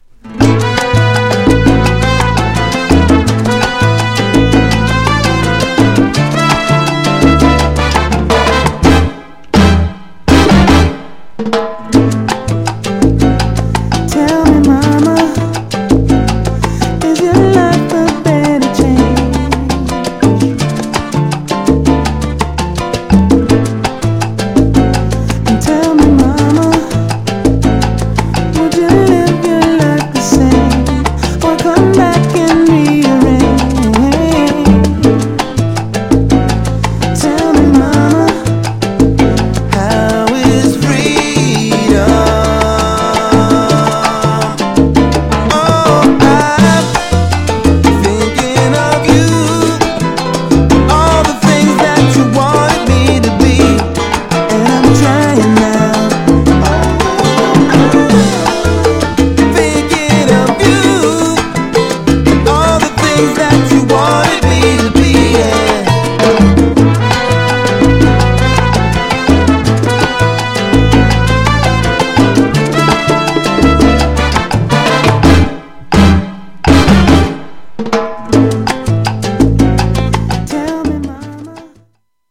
母に捧げる狂おしいほどのバラッド!!
GENRE Dance Classic
BPM 81〜85BPM